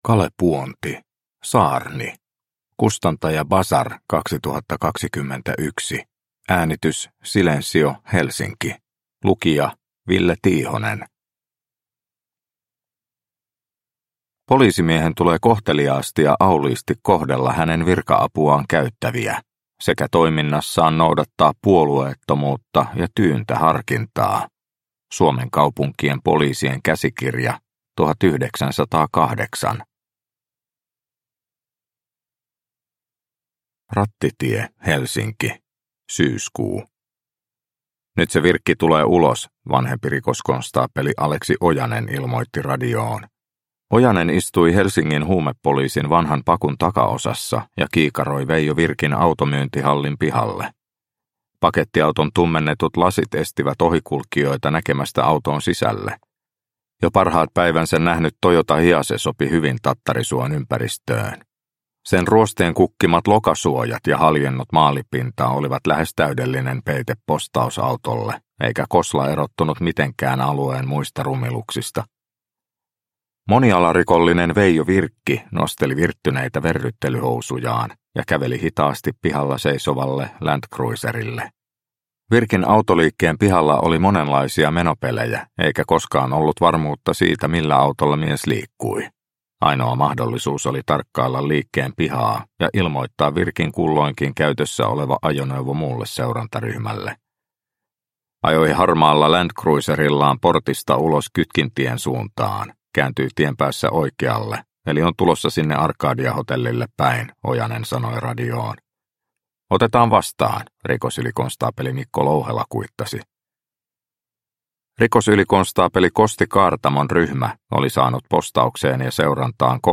Saarni – Ljudbok – Laddas ner